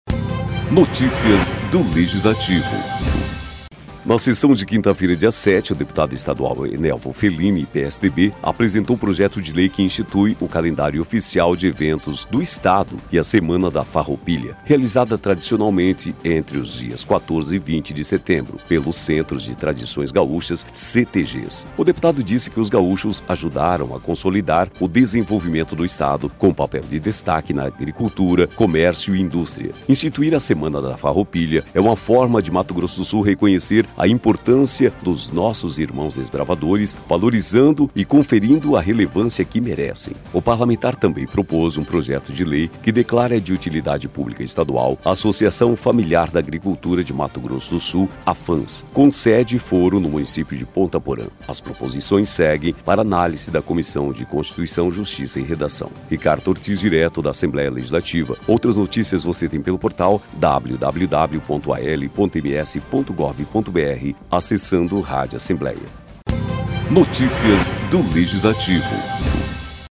Na sessão desta quinta-feira (7), o deputado estadual Enelvo Felini (PSDB) apresentou projeto de lei que inclui no Calendário Oficial de Eventos do Estado a Semana da Farroupilha, realizada tradicionalmente entre os dias 14 e 20 de setembro, pelos Centros de Tradições Gaúchas (CTGs).